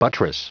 Prononciation du mot buttress en anglais (fichier audio)